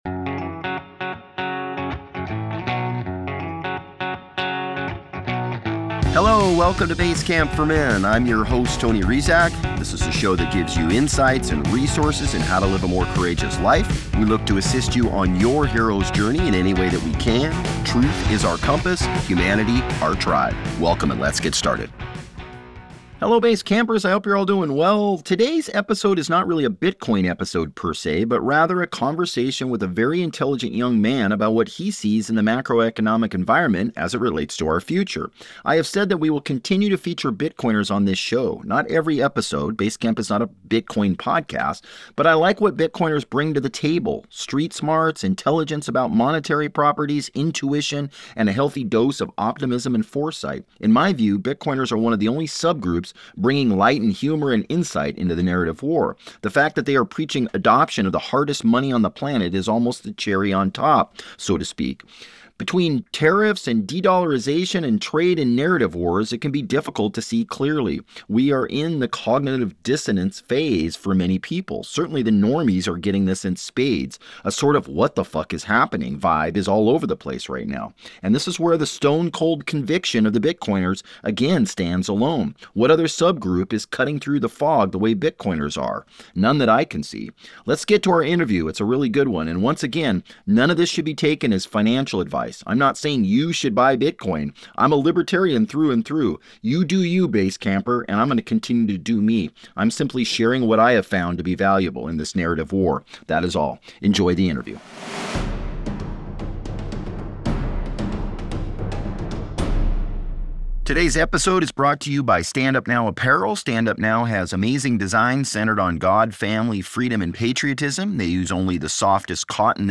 Today’s episode is not really a Bitcoin episode per se. But rather a conversation with a very intelligent young man about what he sees in the macroeconomic environment as it relates to our future.